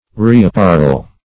Reapparel \Re`ap*par"el\ (r[=e]`[a^]p*p[a^]r"[e^]l)